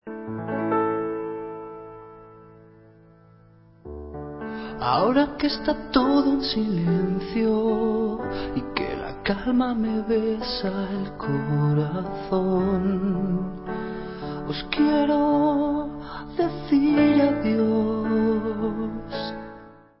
sledovat novinky v kategorii Rock
sledovat novinky v oddělení Heavy Metal